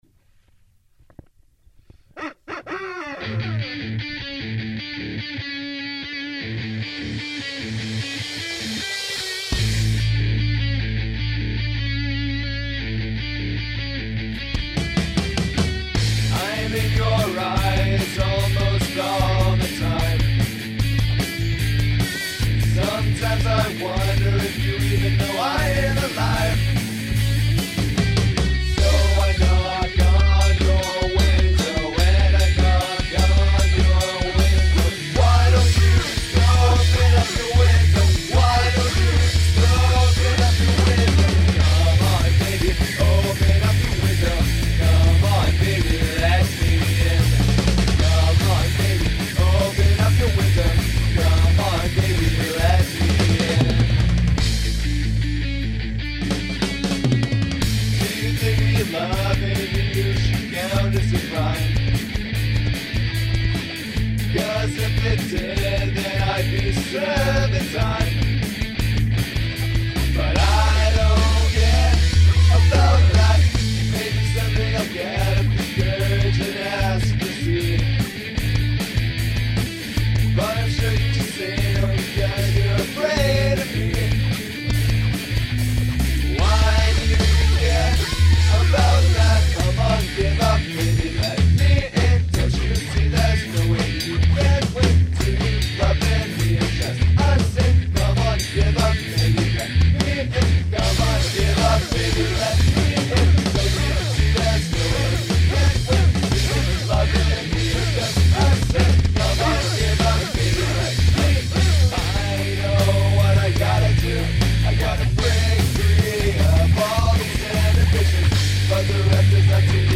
Uncommon Percussion